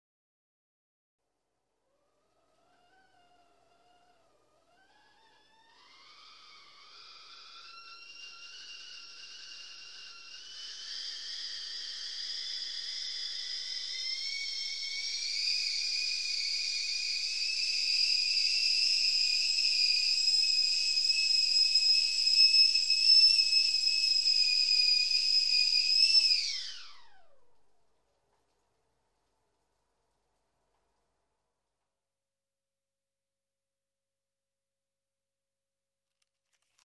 Kettle boiling and whistling 41114
• Category: Electric kettle
• Quality: High